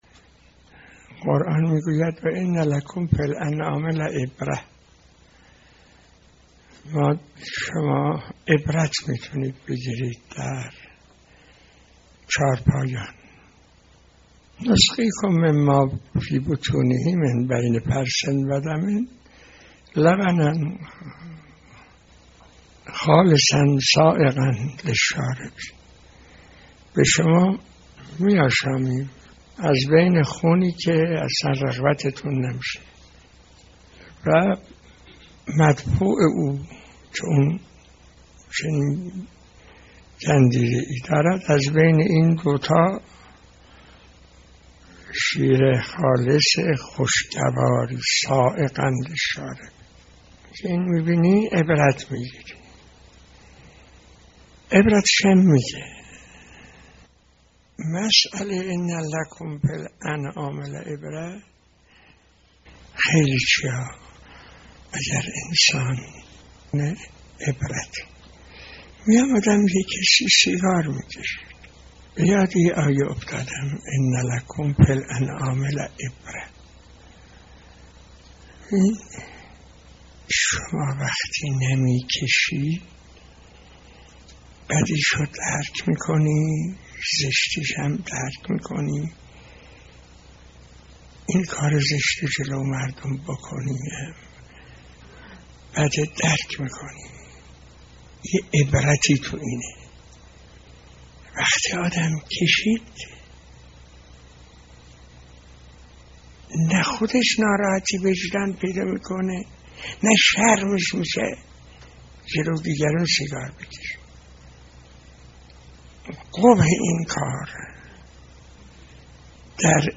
درس اخلاق | چگونه به حیات طیبه برسیم؟
به گزارش خبرگزاری حوزه، مرحوم آیت الله حائری شیرازی در یکی از دروس اخلاق به موضوع «رابطه علم و عمل» پرداخت که تقدیم شما فرهیختگان می شود.